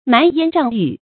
蠻煙瘴雨 注音： ㄇㄢˊ ㄧㄢ ㄓㄤˋ ㄧㄩˇ 讀音讀法： 意思解釋： 指南方有瘴氣的煙雨。